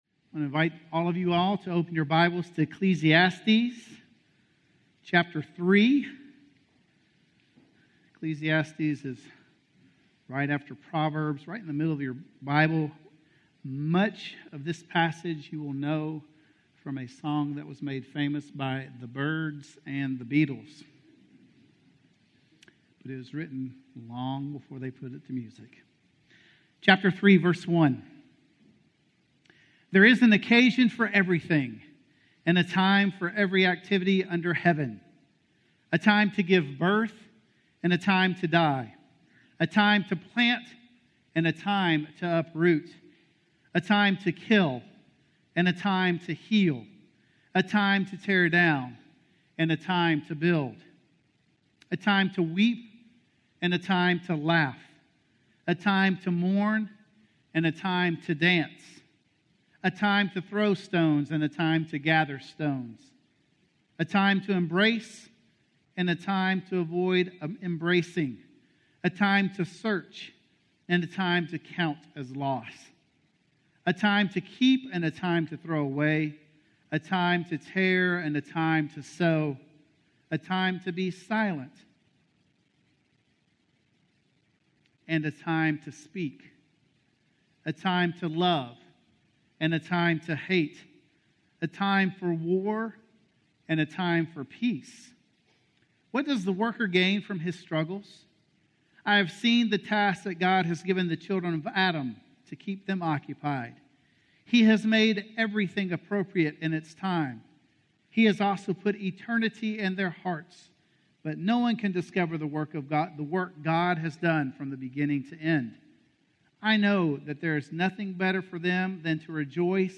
A Time to Let Go - Sermon - Woodbine